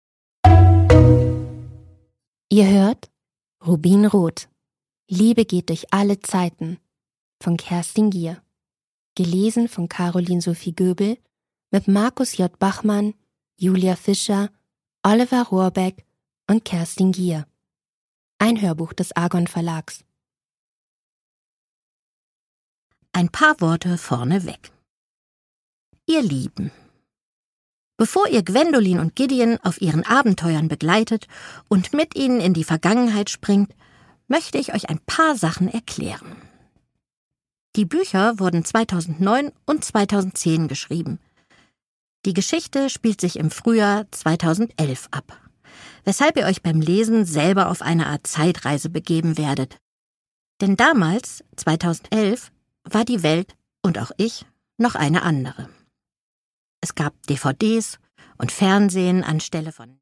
Die Edelstein-Trilogie, Band 1 (Ungekürzte Lesung)
Der Name de Villiers wird in Übereinstimmung mit Kerstin Gier in diesem Hörbuch korrekt englisch ausgesprochen, da es sich zwar ursprünglich um ein französisches Geschlecht handelte, der englische Zweig der Familie aber mittlerweile englisch ausgesprochen wird.